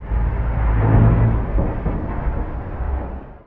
metal_low_creaking_ship_structure_12.wav